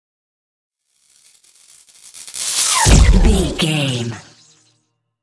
Whoosh to hit sci fi disappear debris
Sound Effects
futuristic
intense
woosh to hit